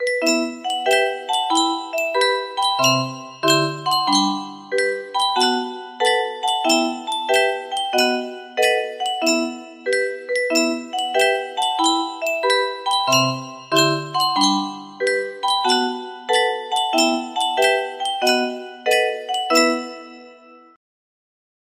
Smetana - The Moldau music box melody